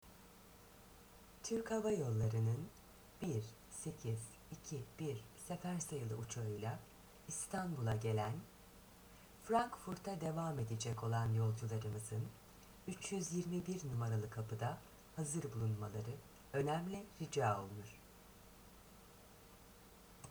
Freelance voice actress.
Sprechprobe: Sonstiges (Muttersprache):
Needless to say, I can change the tone of my voice easily. I speak with clear diction.
Havalimanı Anons 007.mp3